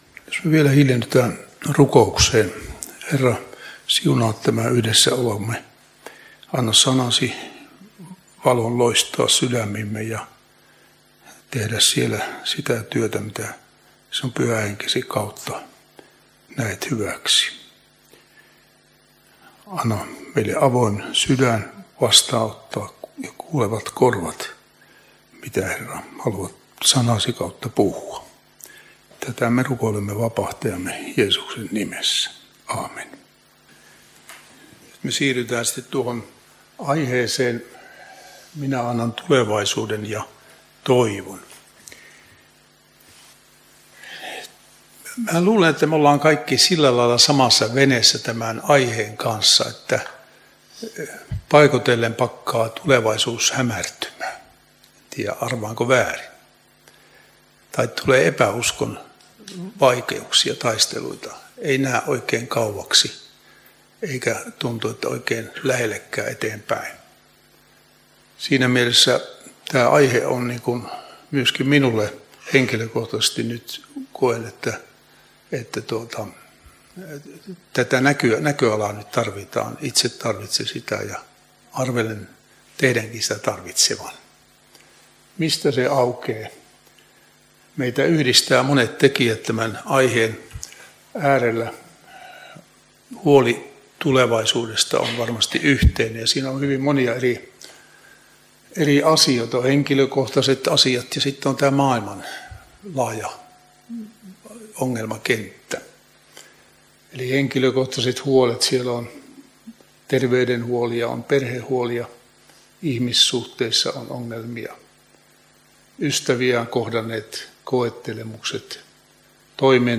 opetus Niinisalossa 3 sunnuntaina loppiaisesta